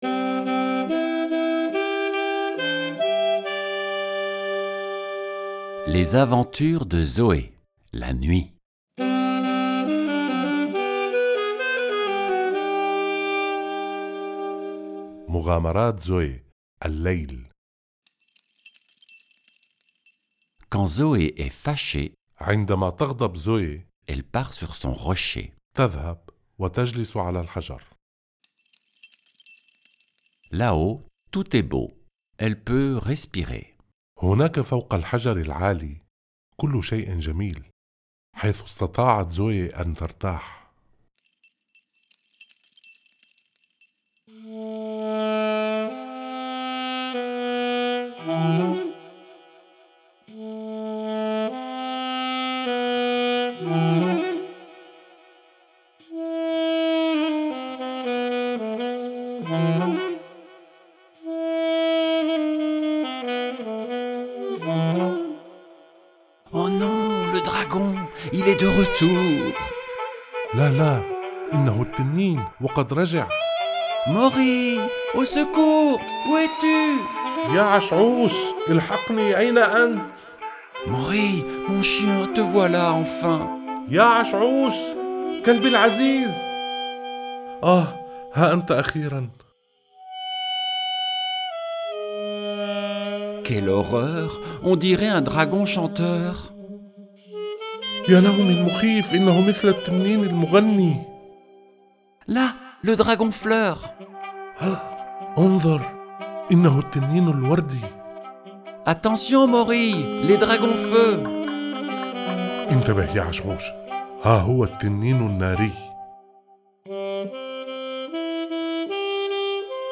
création musicale, enregistrement et mixage de la musique
enregistrement et mixage des voix
lecture en français
lecture en arabe